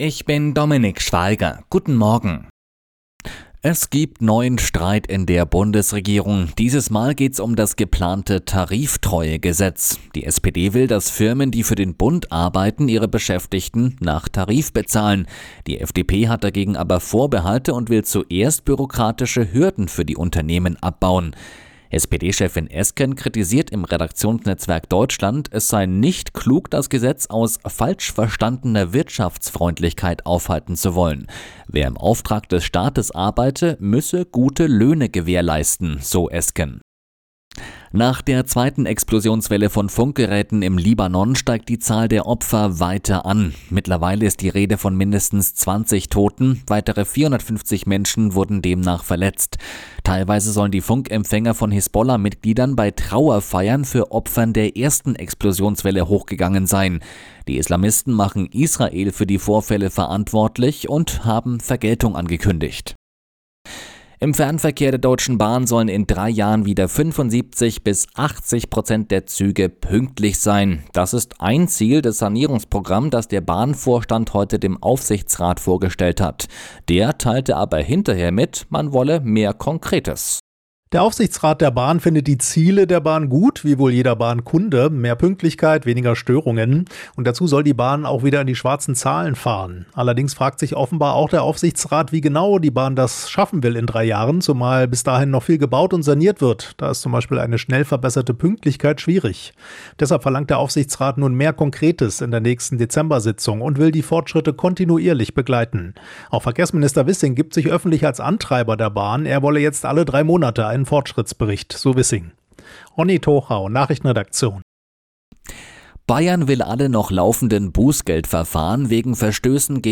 Die Arabella Nachrichten vom Donnerstag, 19.09.2024 um 04:59 Uhr - 19.09.2024